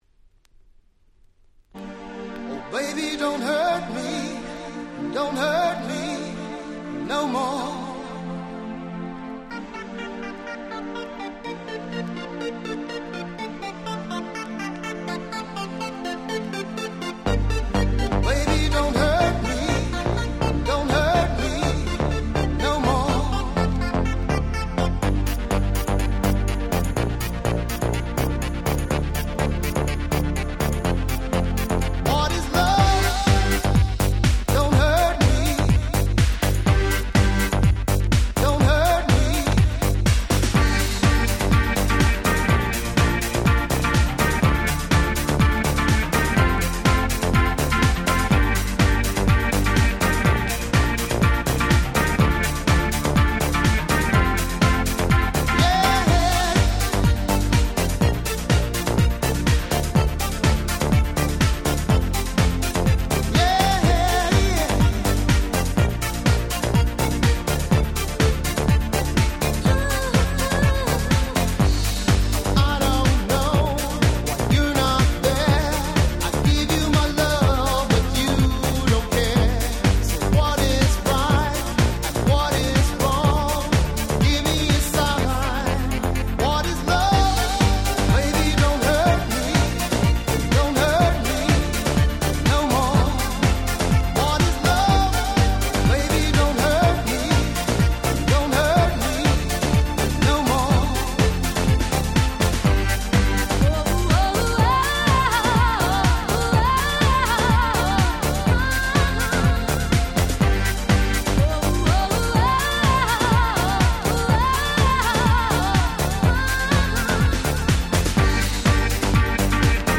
92' Club Music Super Classics !!